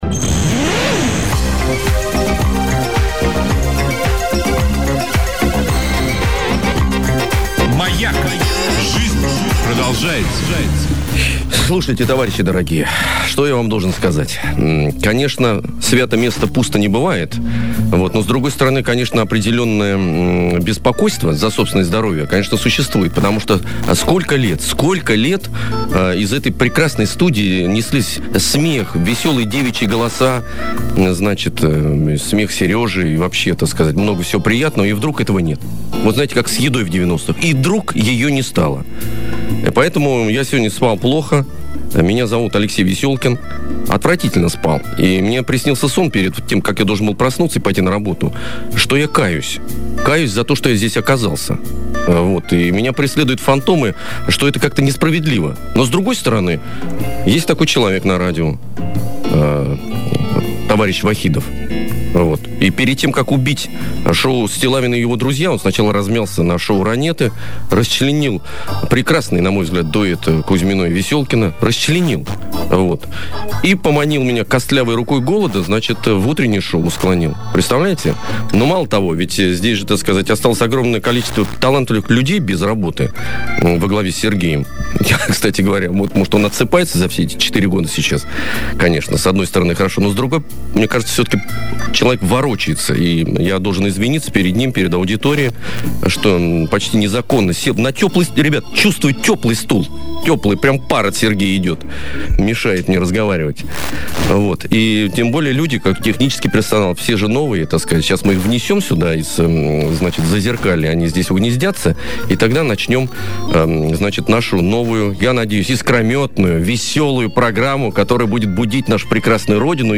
Хр.:10'00" | Теги : Сергей Стиллавин , шоу пропаганда , радиоведущий , диджей , запись эфира , стиллавин и его друзья , утреннее шоу Прощание "Стиллавин и его друзья".